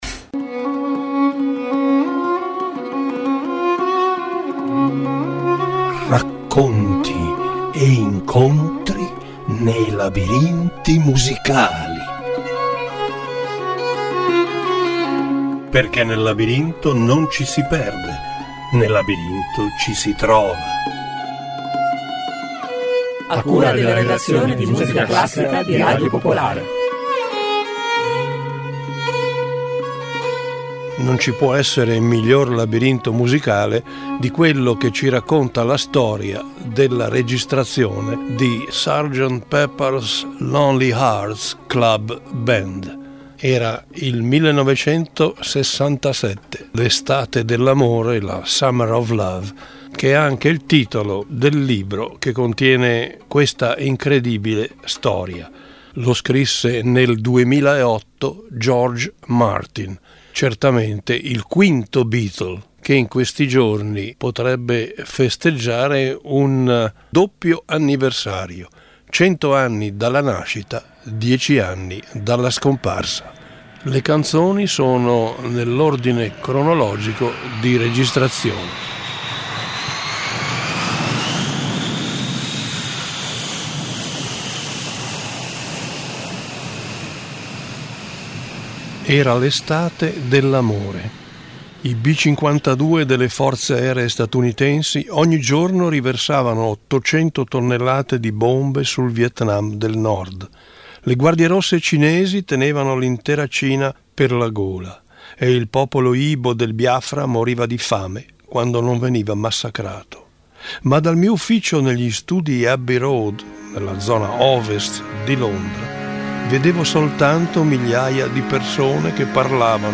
"Labirinti Musicali" ideato dalla redazione musicale classica di Radio Popolare, in ogni episodio esplora storie, aneddoti e curiosità legate alla musica attraverso racconti che intrecciano parole e ascolti.